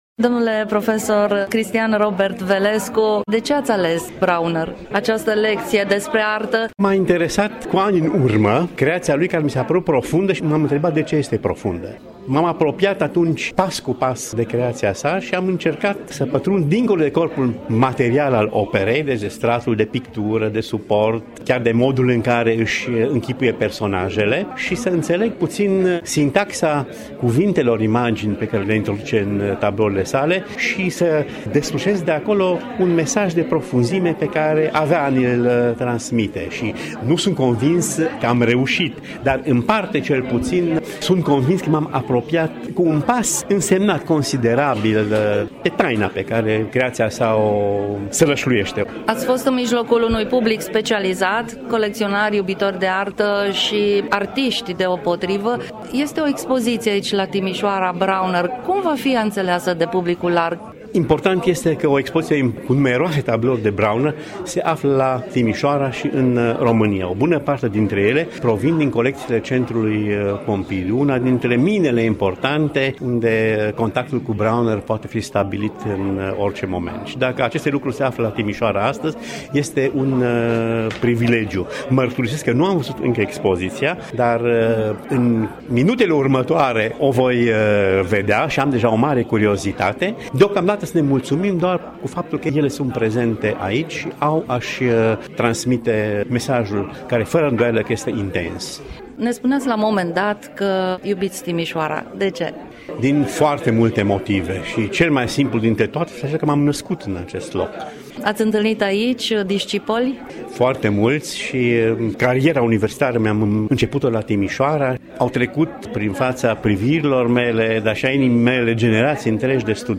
interviu difuzat în emisiunea „Cultura la doi pași”, Radio Timișoara